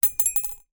Звуки стука зубов
зуб со звоном плюхнулся в стакан